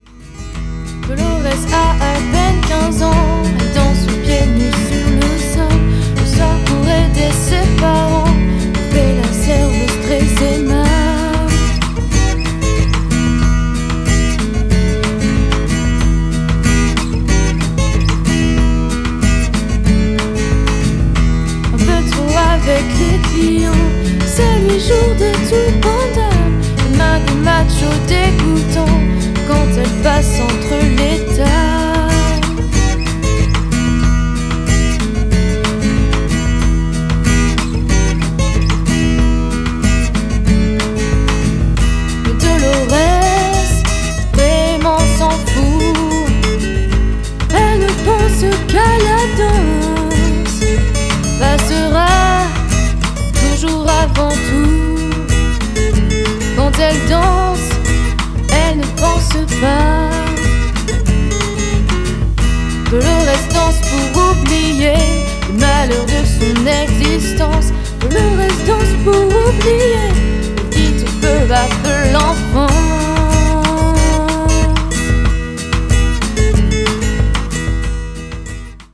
en version acoustique